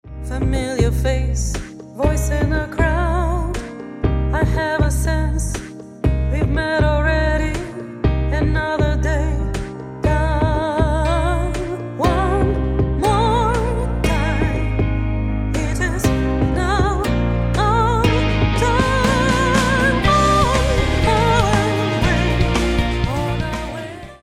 Backup vocals
Bass guitar
Drums